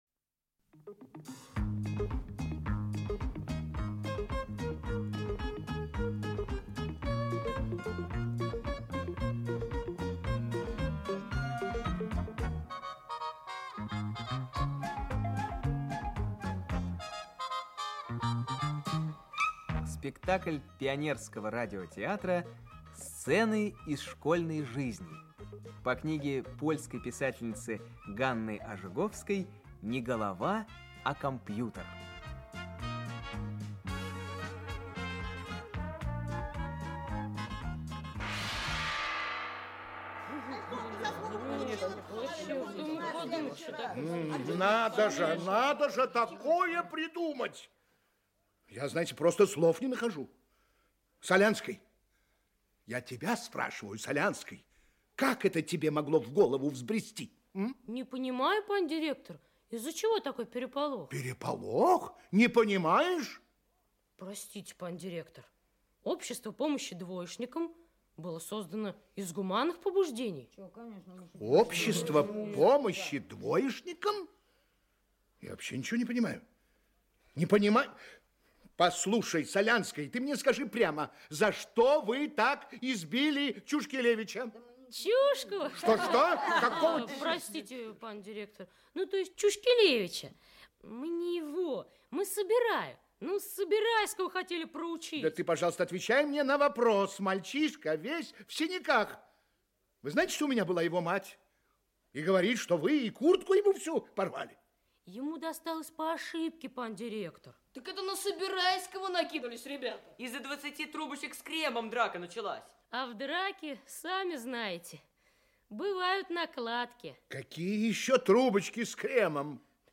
Аудиокнига Сцены из школьной жизни | Библиотека аудиокниг
Aудиокнига Сцены из школьной жизни Автор Ганна Ожоговская Читает аудиокнигу Актерский коллектив.